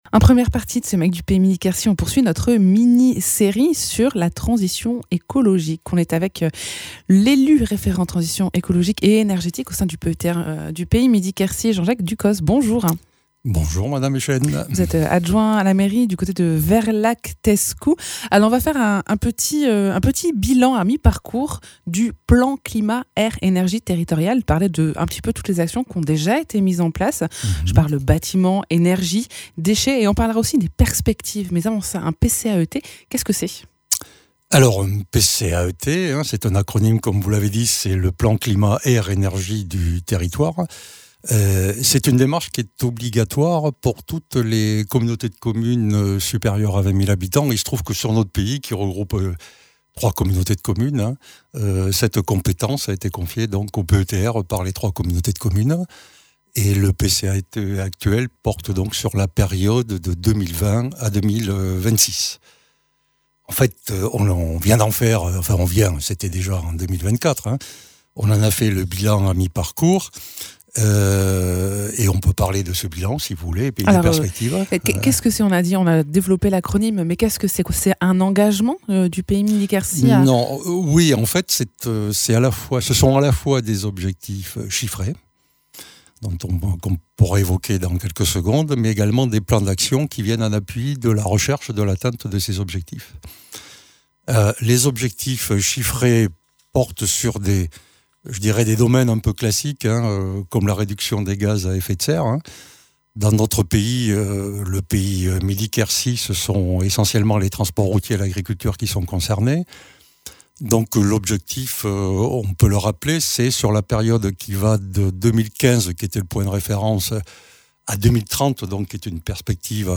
Invité(s) : Jean-Jacques Ducos, élu référent transition énergétique au Pays Midi-Quercy